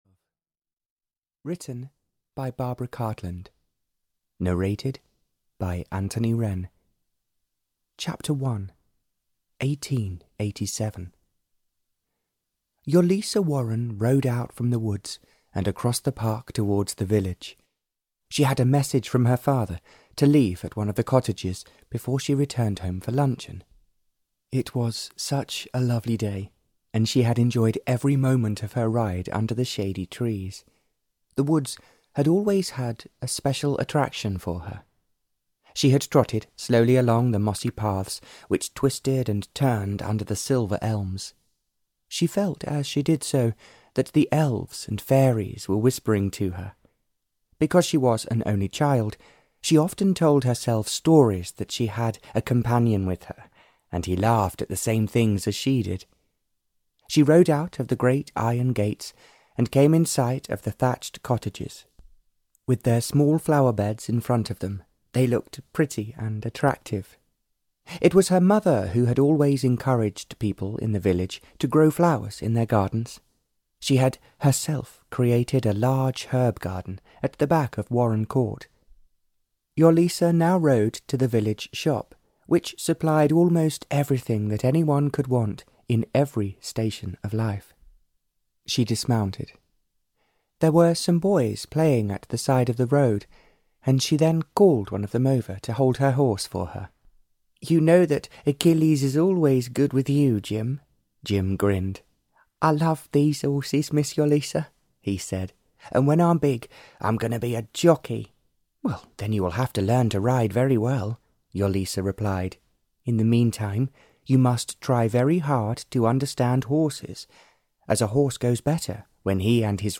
One Minute to Love (Barbara Cartland's Pink Collection 137) (EN) audiokniha
Ukázka z knihy